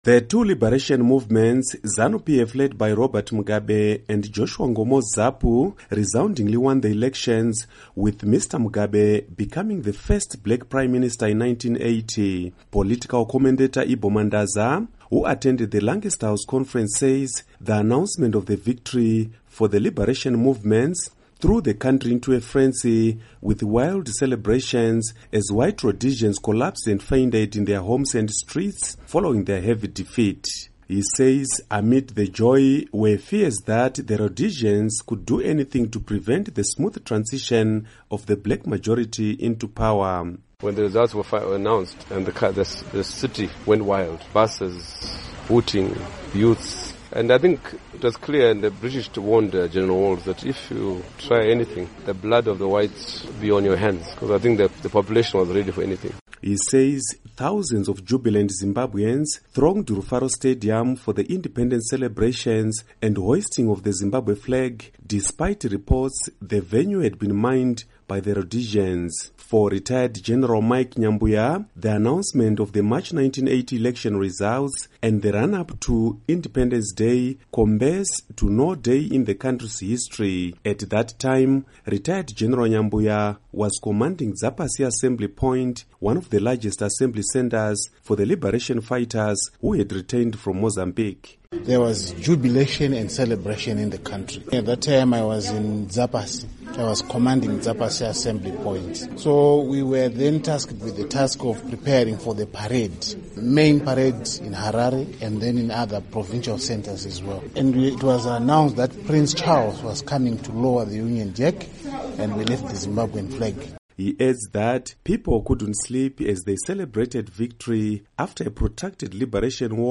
Report on Zimbabwe Independence